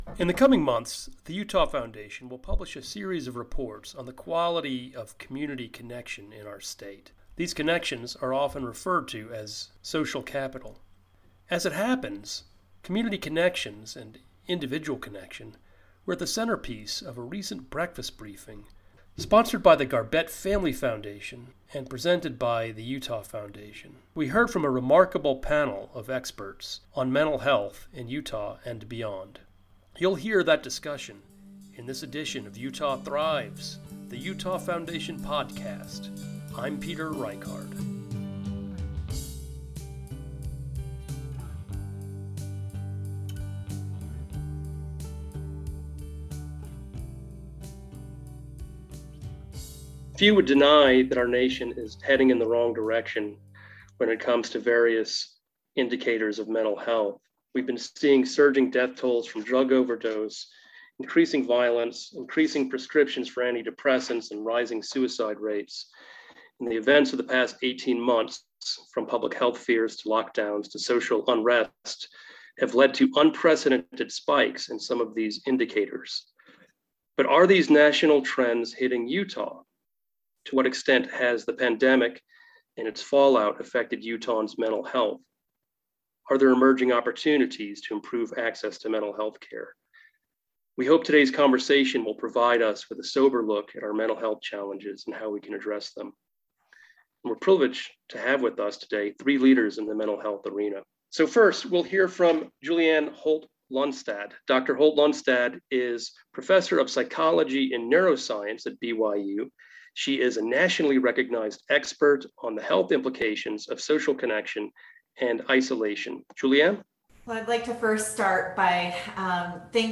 The conversation is excerpted from a recent Utah Foundation Breakfast Briefing sponsored by the Garbett Family Foundation.